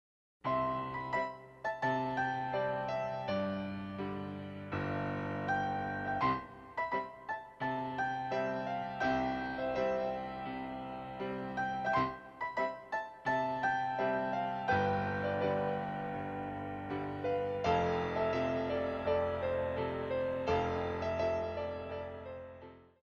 34 Piano Selections